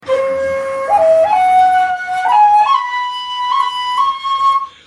Shakuhachi 49